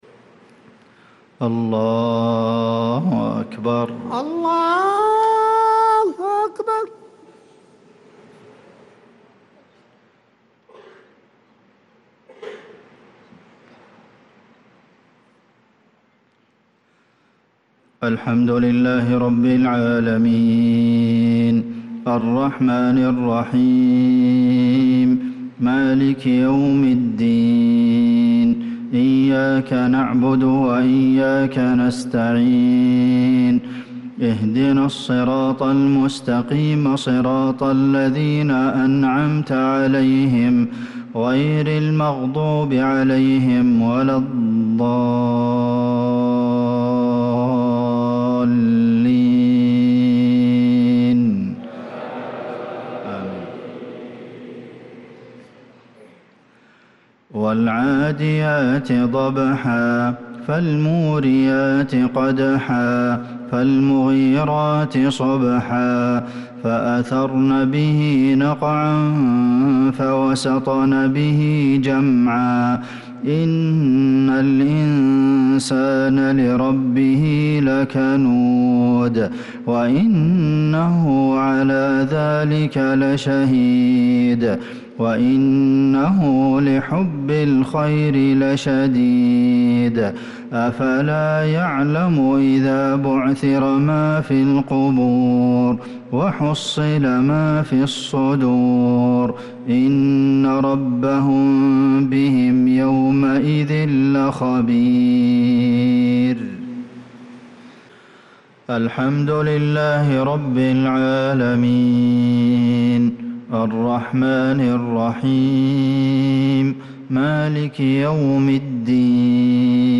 صلاة المغرب للقارئ عبدالمحسن القاسم 24 محرم 1446 هـ
تِلَاوَات الْحَرَمَيْن .